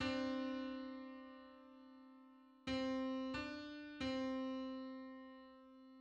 Public domain Public domain false false This media depicts a musical interval outside of a specific musical context.
9261st_harmonic_on_C.mid.mp3